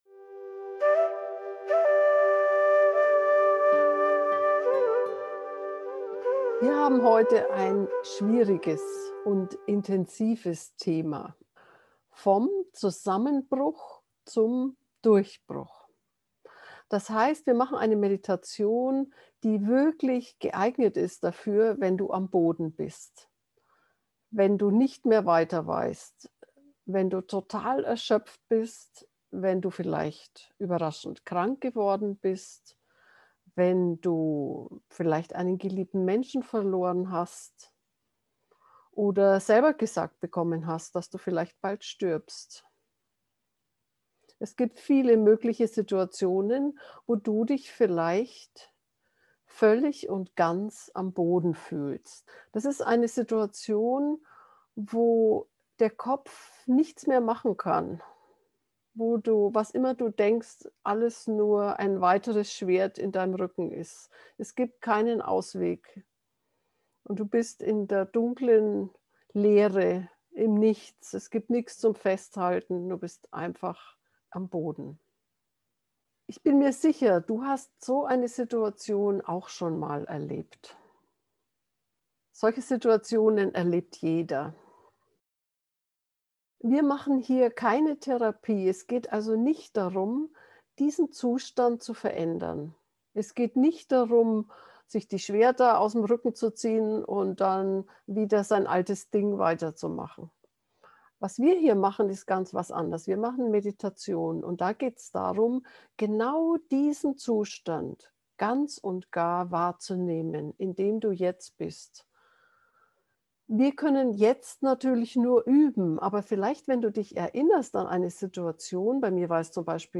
zusammenbruch-durchbruch-gefuehrte-meditation